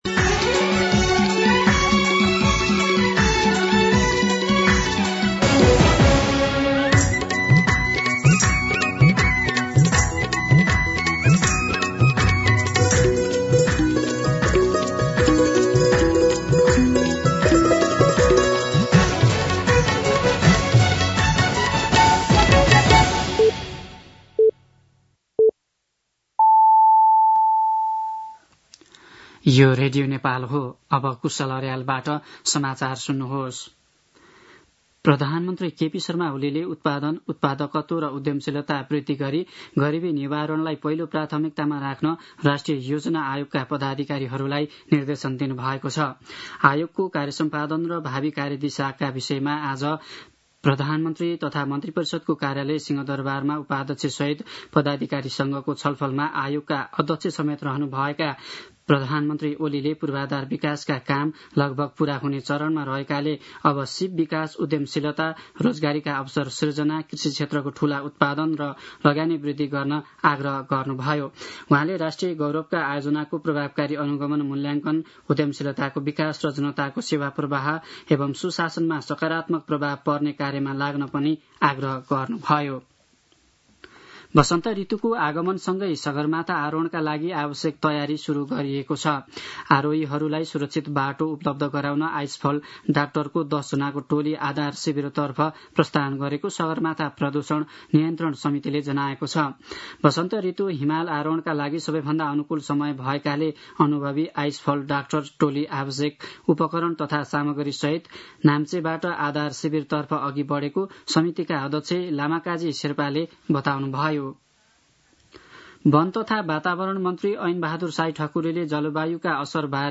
दिउँसो ४ बजेको नेपाली समाचार : १ चैत , २०८१
4pm-News-01.mp3